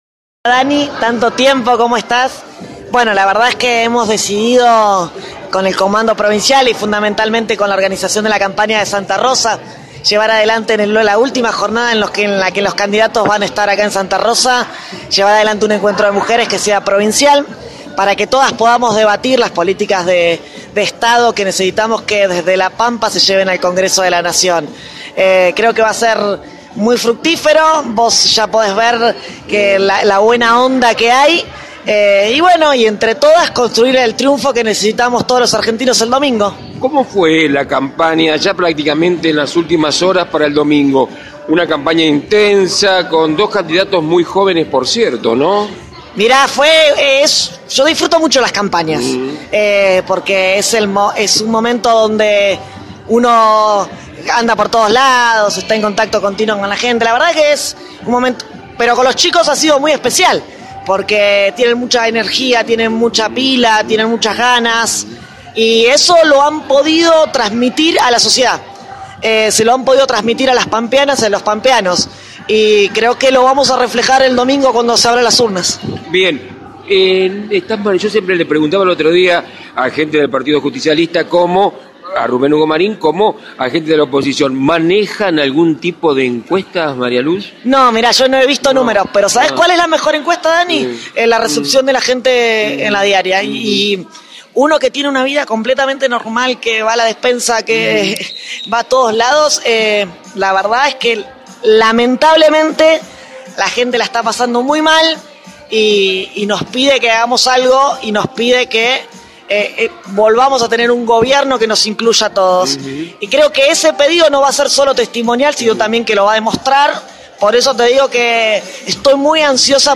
En el dia de ayer, se realizó un encuentro de mujeres en el Centro Empleados de Comercio, de la Capital pampeana.